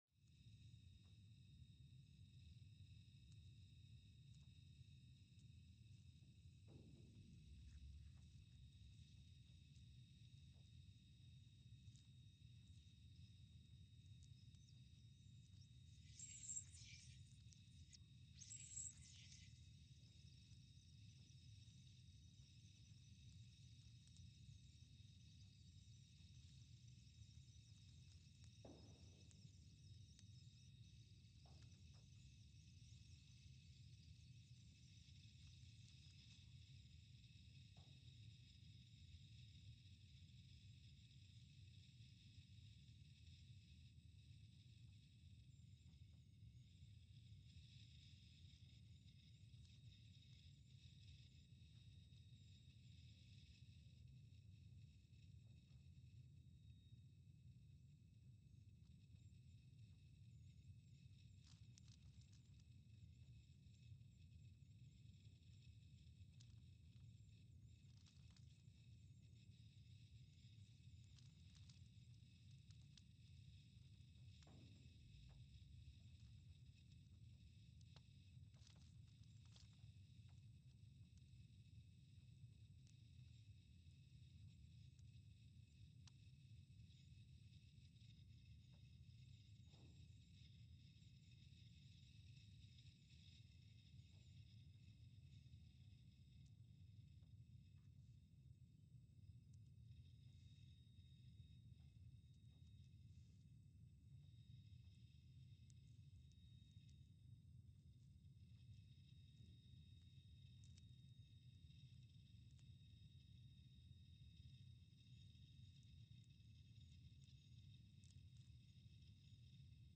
Scott Base, Antarctica (seismic) archived on July 16, 2020
Station : SBA (network: IRIS/USGS) at Scott Base, Antarctica
Sensor : CMG3-T
Speedup : ×500 (transposed up about 9 octaves)
Gain correction : 25dB
SoX post-processing : highpass -2 90 highpass -2 90